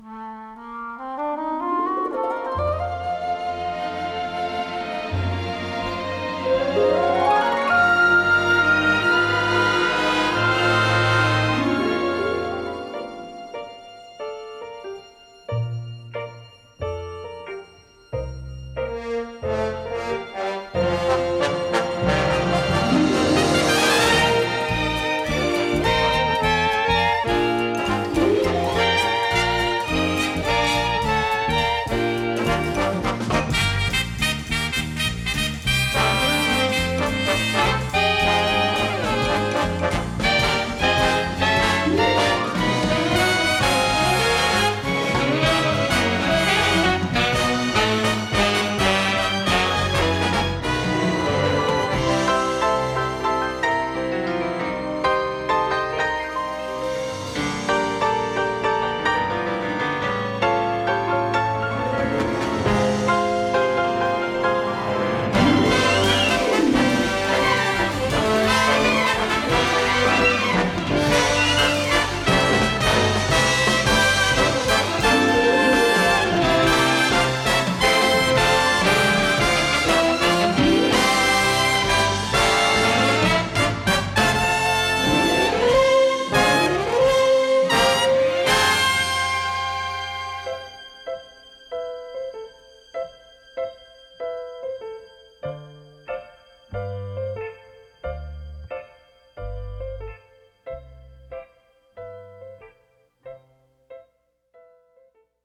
SAXOFÓN